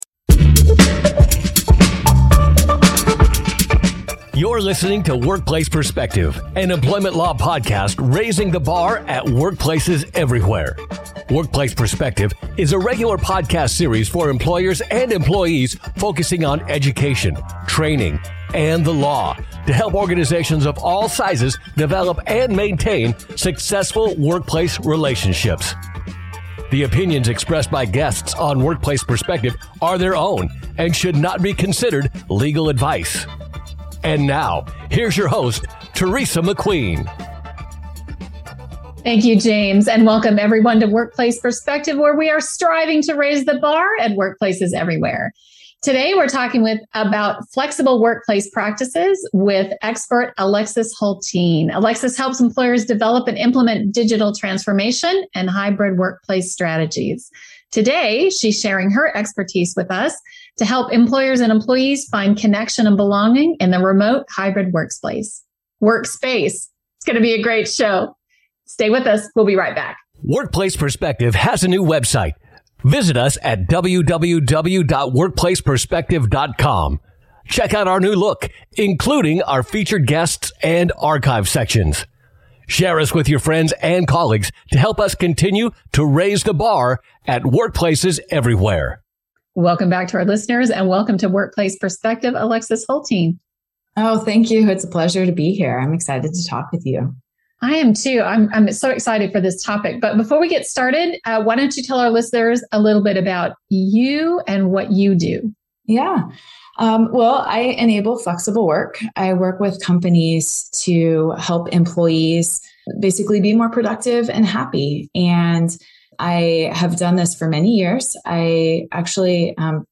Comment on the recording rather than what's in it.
00:06Introduction and Disclaimer 01:29Employers gravitating towards remote work 12:24Break and Public Service Announcement